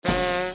no_match_sfx.mp3